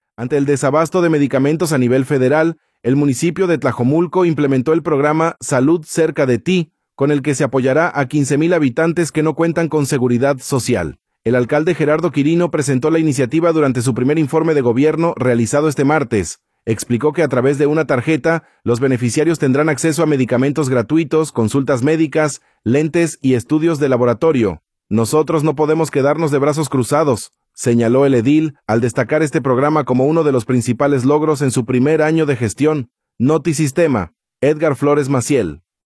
El alcalde Gerardo Quirino presentó la iniciativa durante su primer informe de gobierno, realizado este martes. Explicó que a través de una tarjeta, los beneficiarios tendrán acceso a medicamentos gratuitos, consultas médicas, lentes y estudios de laboratorio.